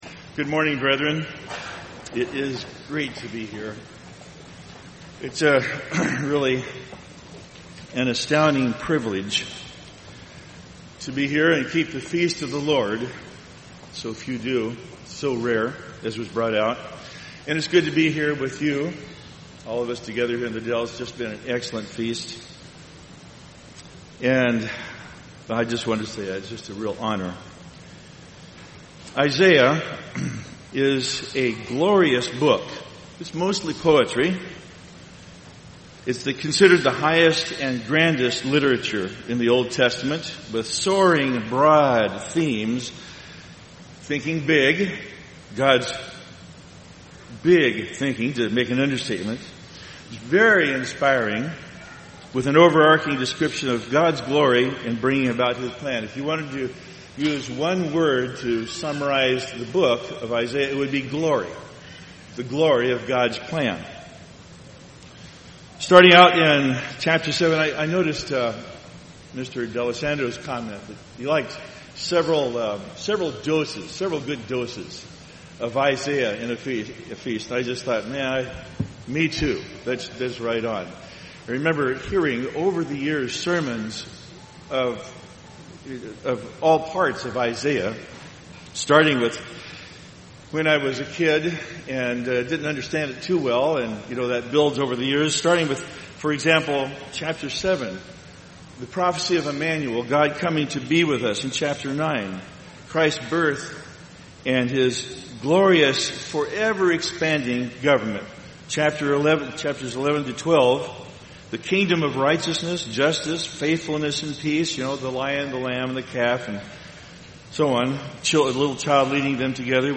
This sermon was given at the Wisconsin Dells, Wisconsin 2014 Feast site.